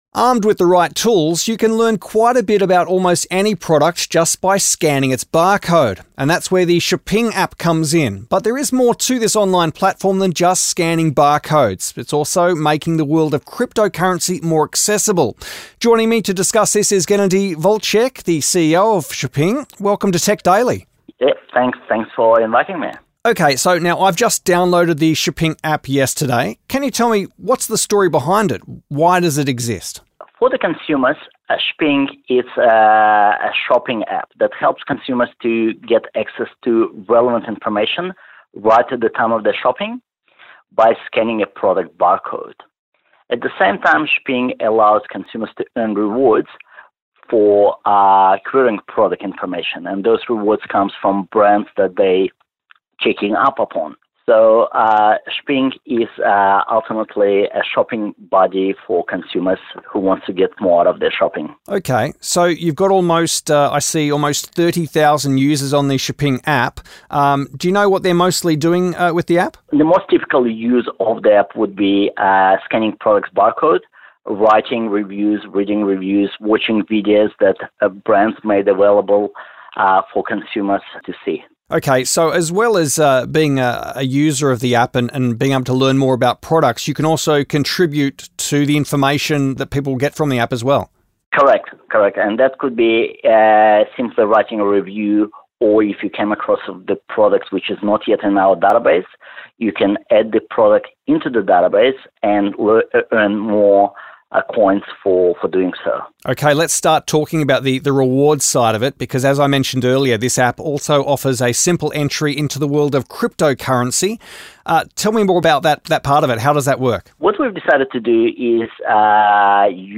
Shping-App-Interview_October-2018.mp3